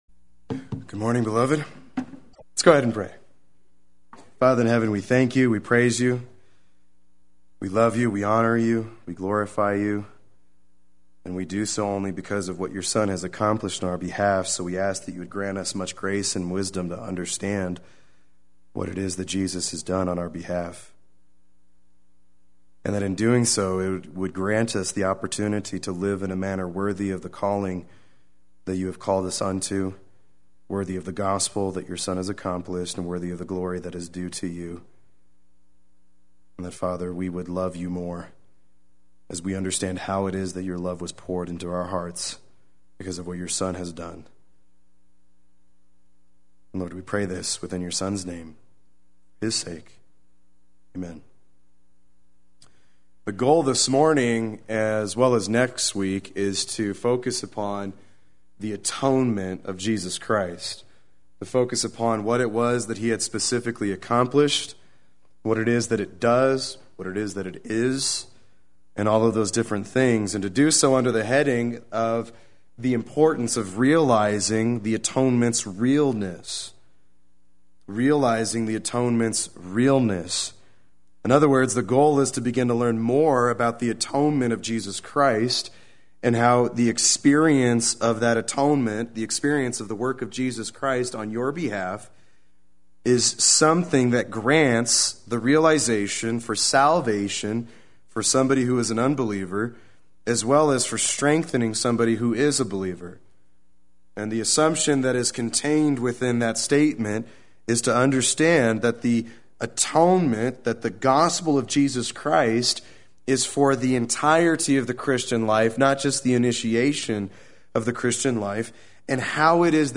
Play Sermon Get HCF Teaching Automatically.
Part 1 Sunday Worship